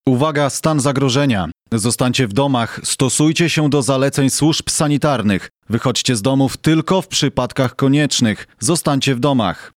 Takie komunikaty z radiowozów straży miejskiej usłyszycie na ulicach Ełku.